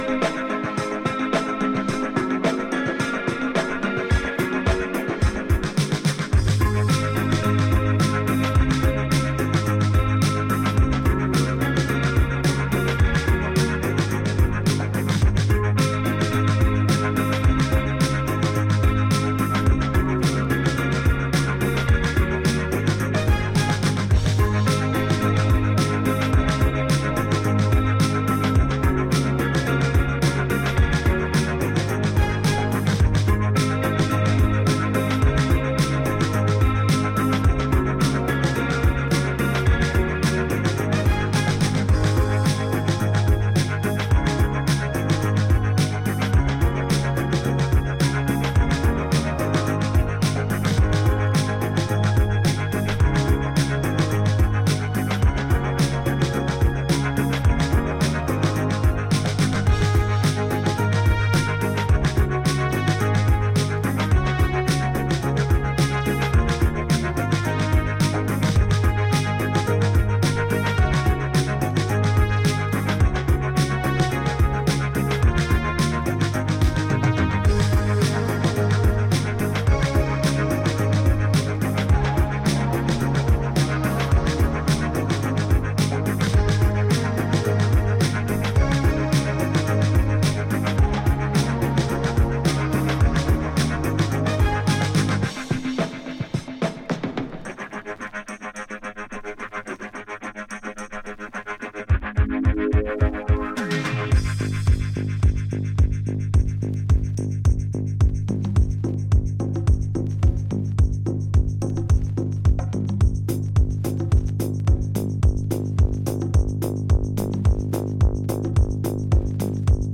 neo-Balearic